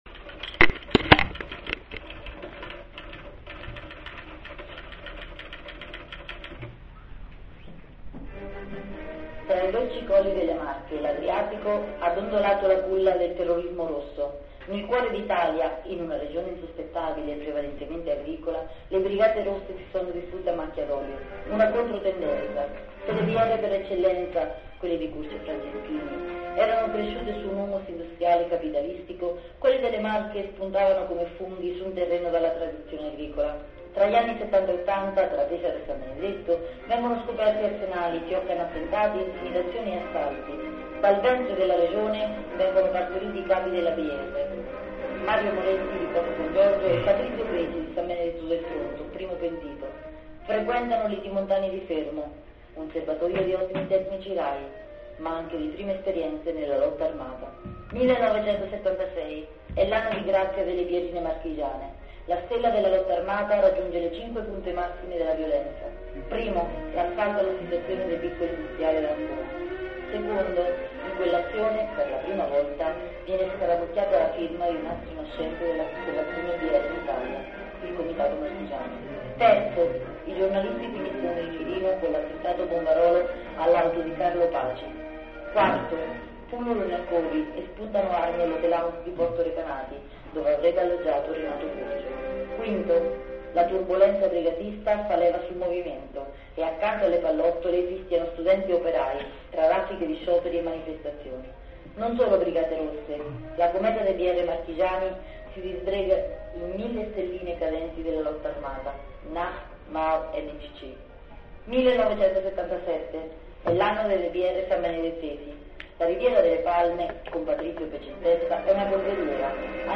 Il secondo incontro della rassegna " Riparliamo degli anni '70 " è andato abbastanza bene.